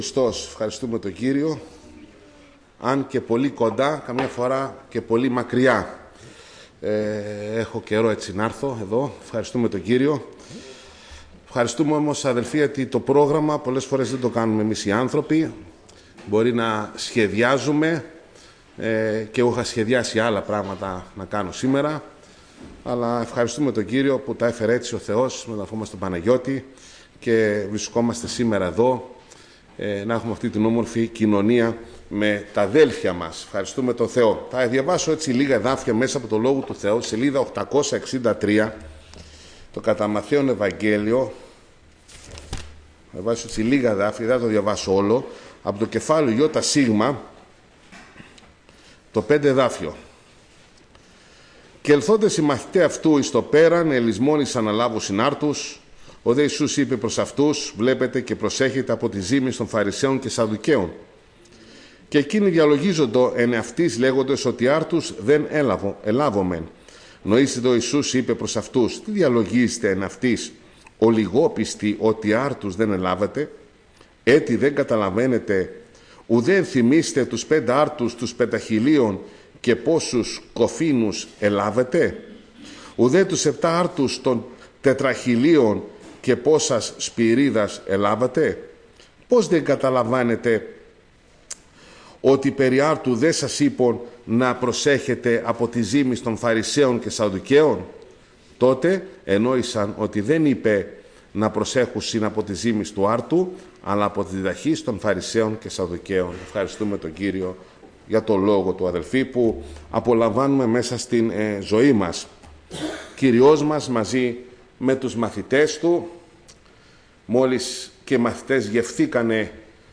Κήρυγμα Παρασκευής
Σειρά: Κηρύγματα